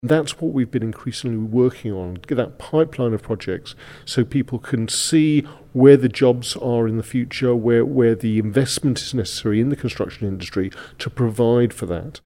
Minister Allinson told Manx Radio it's important to stagger new builds: Listen to this audio